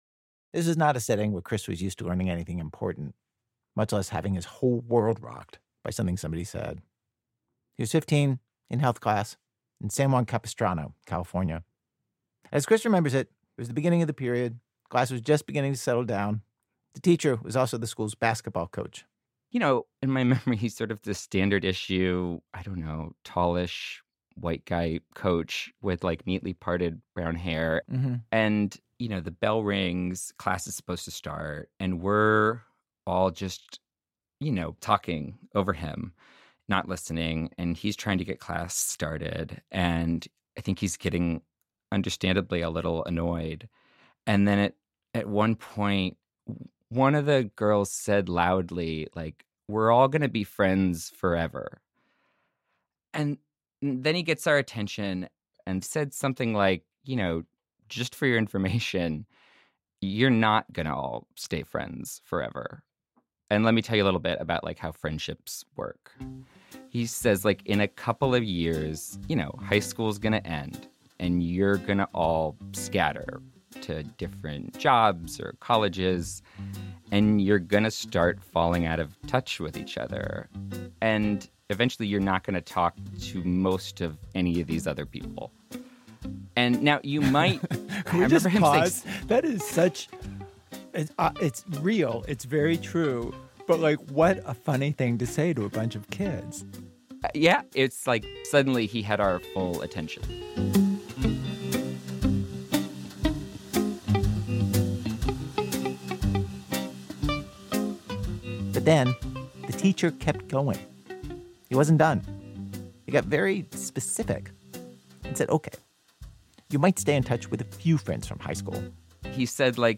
Note: The internet version of this episode contains un-beeped curse words.